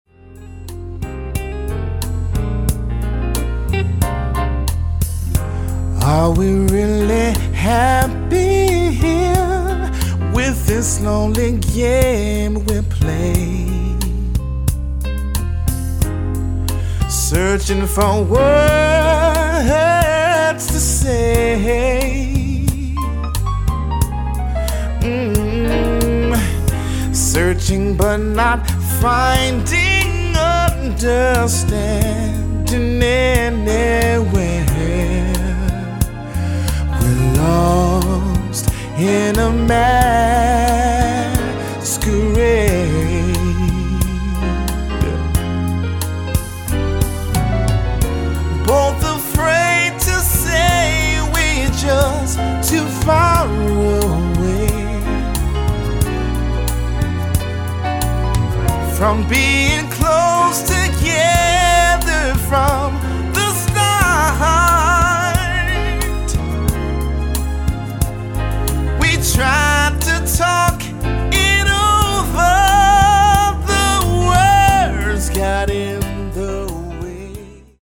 Lounge